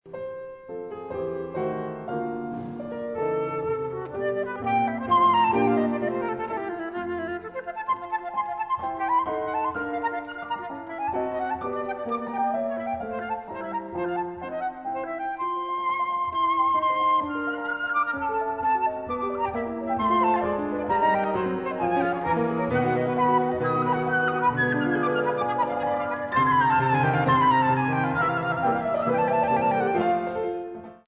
Sonate B-Dur für Flöte und Klavier
Allegro vivace